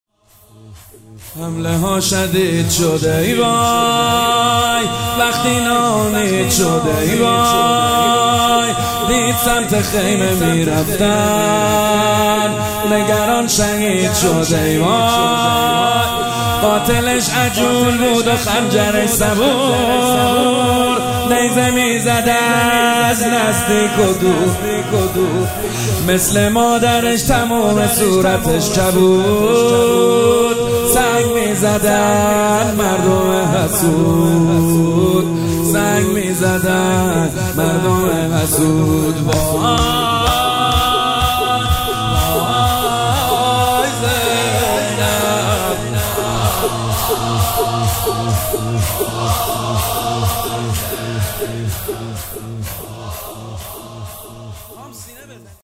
شهادت حضرت زینب -شور - حمله ها شدید شد ای وای - محمد حسین حدادیان